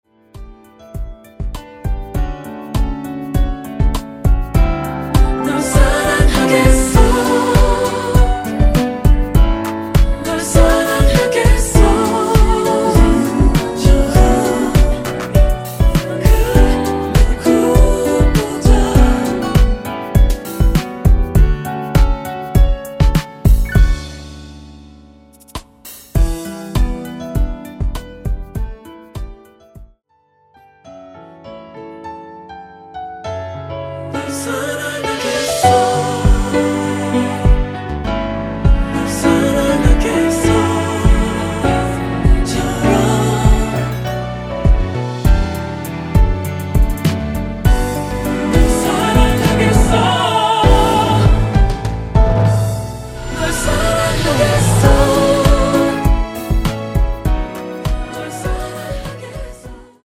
원키에서(-3)내린 코러스 포함된 버젼 입니다.(미리듣기 참조 하세요)
앞부분30초, 뒷부분30초씩 편집해서 올려 드리고 있습니다.
중간에 음이 끈어지고 다시 나오는 이유는